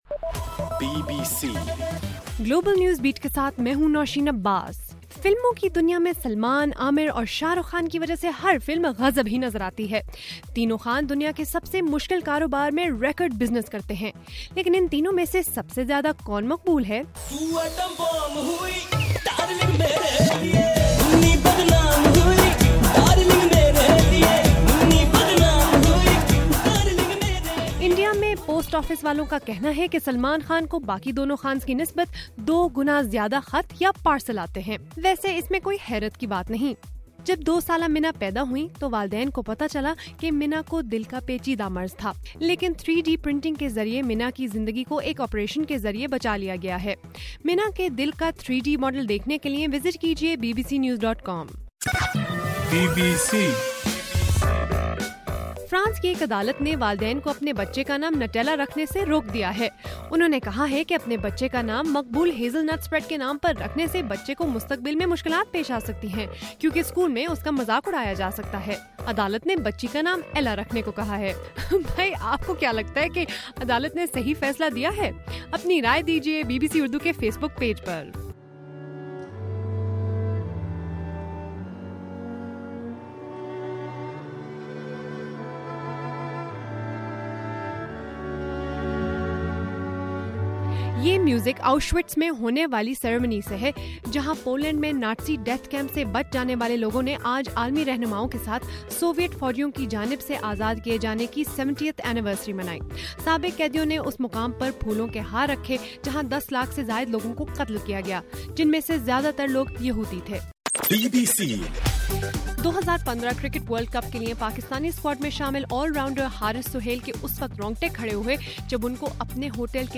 جنوری 27: رات 12 بجے کا گلوبل نیوز بیٹ بُلیٹن